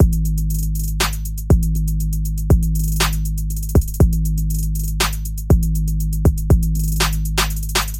LFE建筑套件 鼓
描述：基本的陷阱鼓，在一些事情上有我自己的扭曲。在套件中的两个合成器上进行分层。
标签： 120 bpm Electronic Loops Drum Loops 1.35 MB wav Key : Unknown
声道立体声